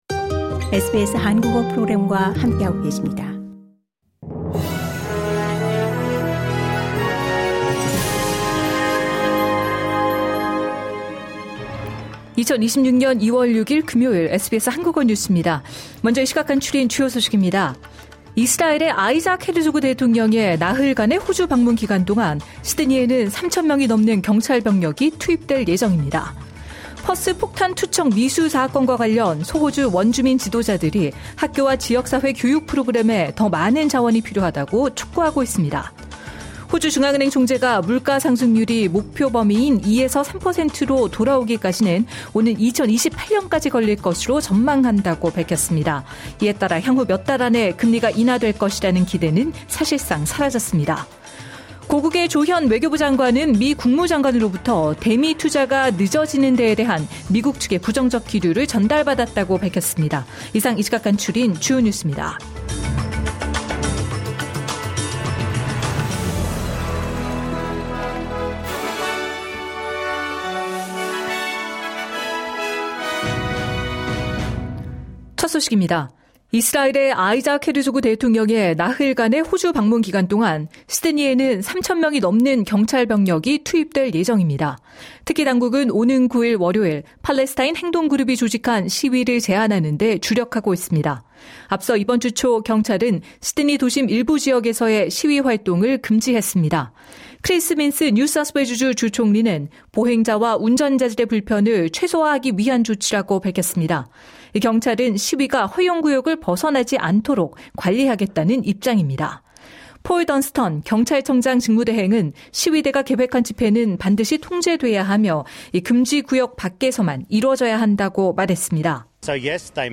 하루 10분 호주 뉴스: 2월 6일 금요일